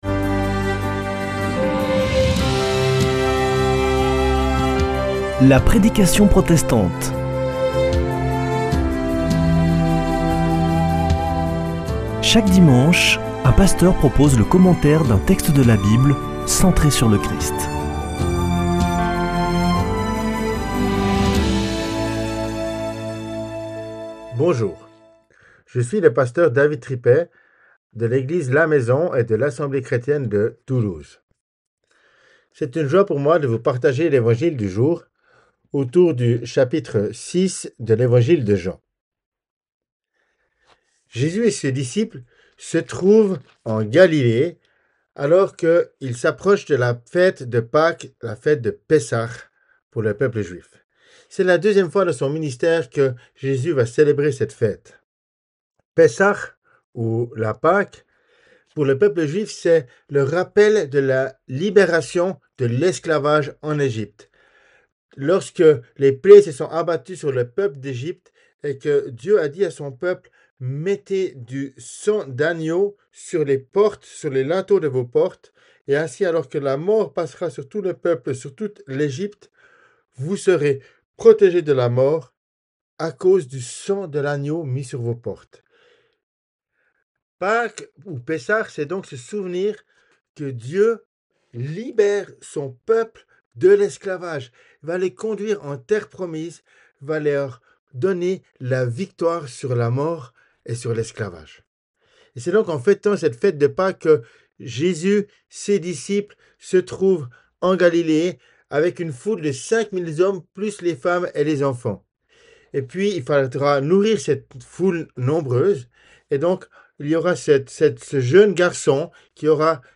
La prédication protestante du 06 avr.